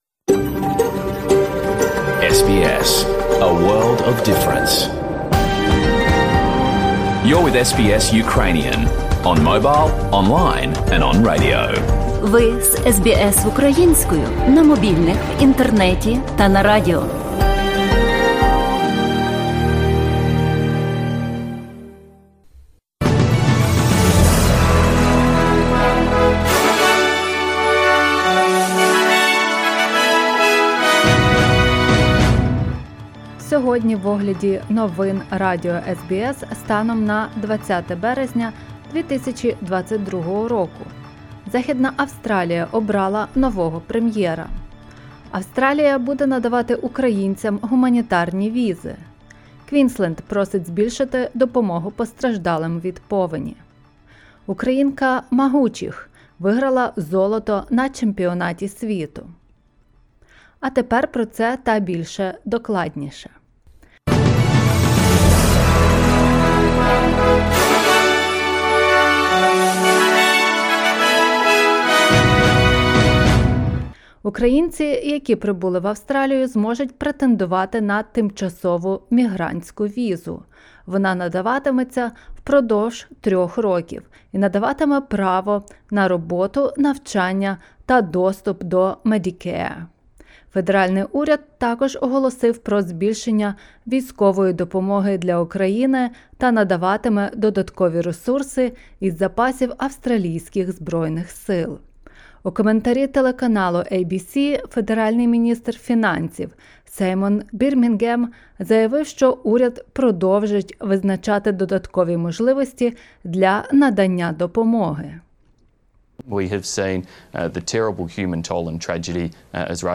Огляд новин станом на 20 березня 2022 року.